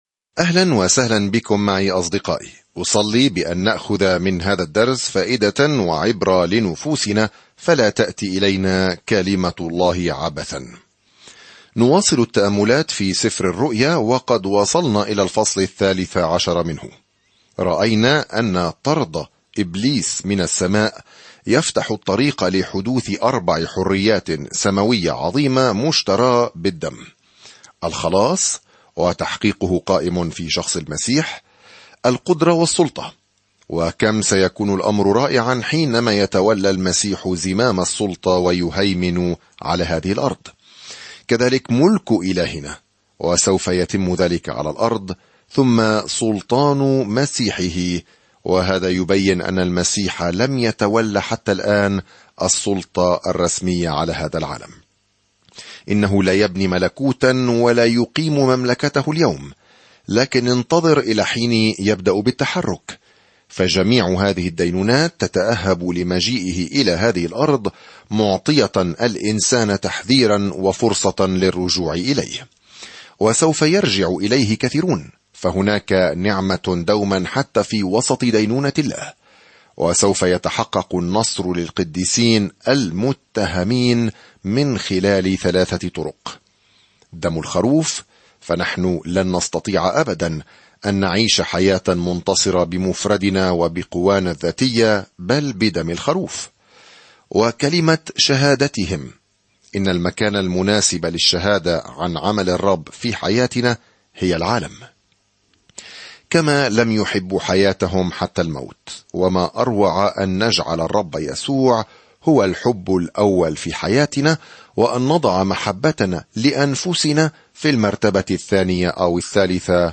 الكلمة رُؤْيَا يُوحَنَّا 2:13-3 يوم 40 ابدأ هذه الخطة يوم 42 عن هذه الخطة يسجل سفر الرؤيا نهاية الجدول الزمني الشامل للتاريخ مع صورة لكيفية التعامل مع الشر أخيرًا وسيملك الرب يسوع المسيح بكل سلطان وقوة وجمال ومجد. سافر يوميًا عبر سفر الرؤيا وأنت تستمع إلى الدراسة الصوتية وتقرأ آيات مختارة من كلمة الله.